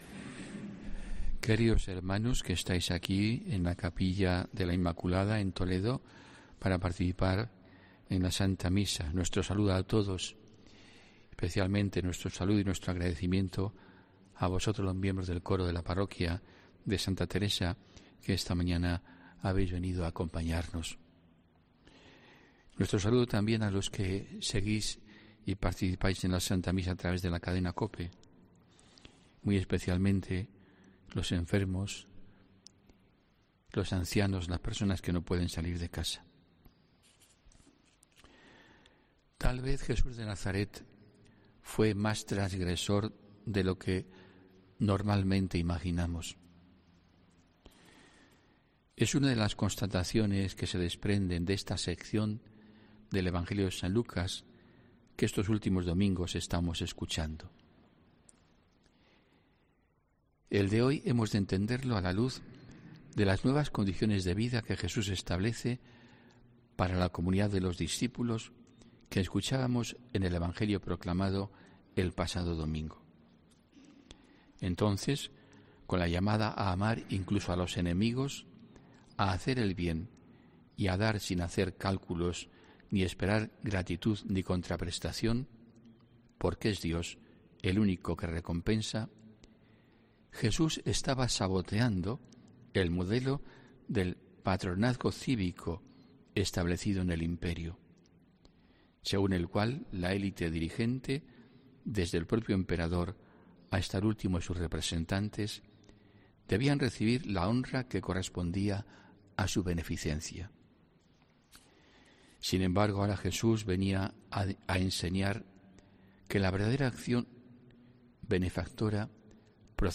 HOMILÍA 27 FEBRERO 2022